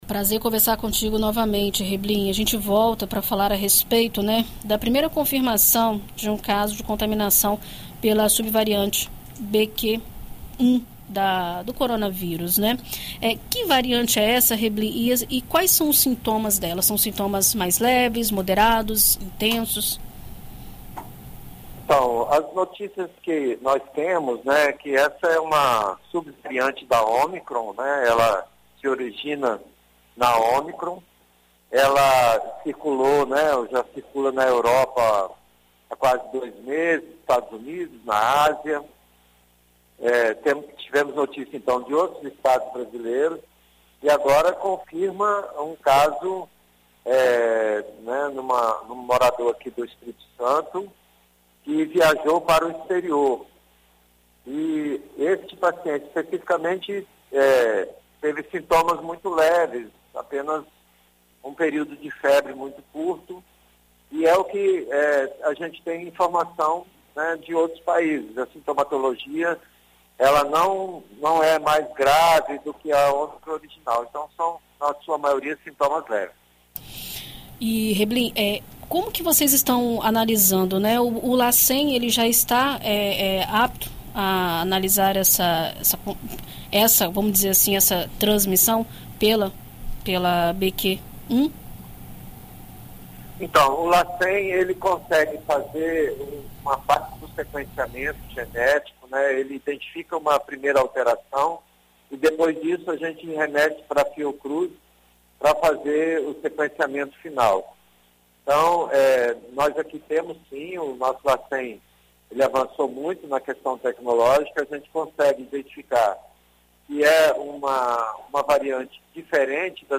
Em entrevista à BandNews FM Espírito Santo nesta terça-feira (08), o subsecretário de Estado de Vigilância em Saúde, Luiz Carlos Reblin, conversa sobre a chegada desta subvariante ao território capixaba e quais estratégias estão sendo tomadas atualmente para frear o avanço do vírus localmente.